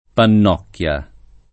vai all'elenco alfabetico delle voci ingrandisci il carattere 100% rimpicciolisci il carattere stampa invia tramite posta elettronica codividi su Facebook Panocchia [ pan 0 kk L a ] o Pannocchia [ pann 0 kk L a ] top.